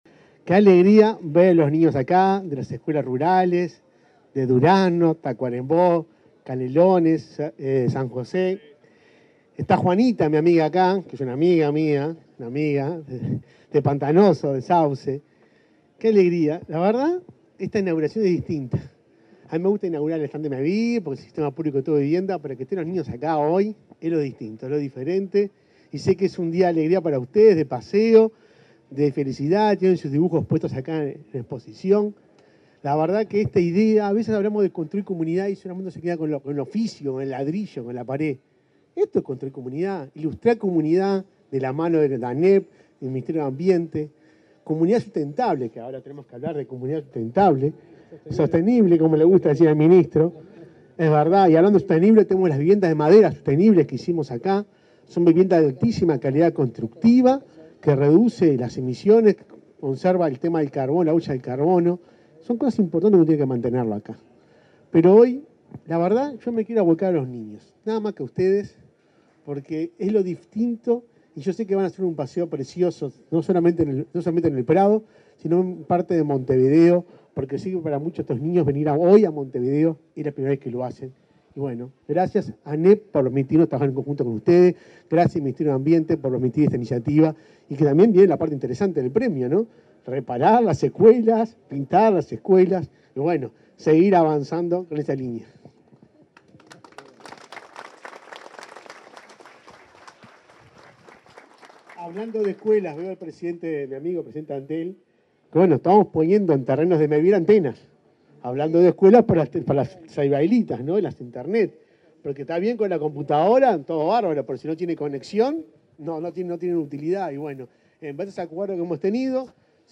Palabras de autoridades en stand de Mevir
Palabras de autoridades en stand de Mevir 12/09/2022 Compartir Facebook X Copiar enlace WhatsApp LinkedIn El presidente de Mevir, Juan Pablo Delgado; el presidente de la Administración Nacional de Educación Pública (ANEP), Robert Silva; el ministro de Ambiente, Adrián Peña, y su par de Ganadería, Fernando Mattos, participaron en la inauguración del stand de Mevir en la Expo Prado.